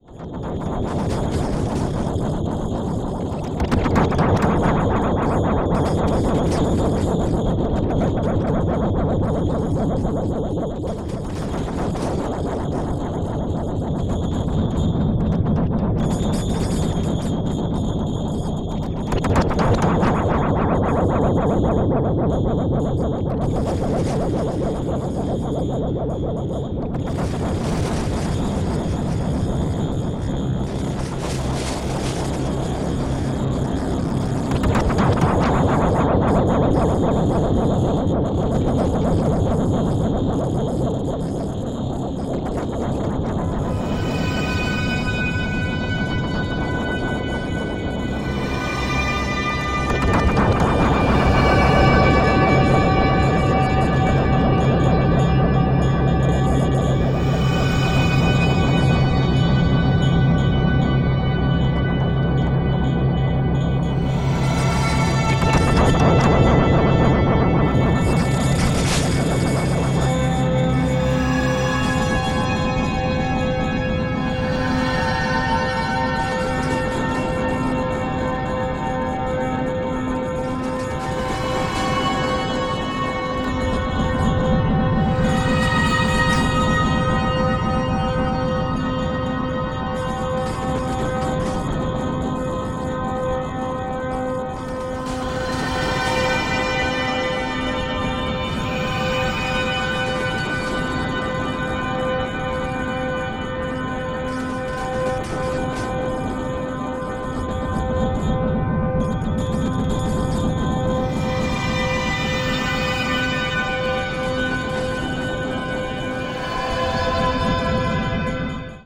Electroacoustic composition in four parts 2014-15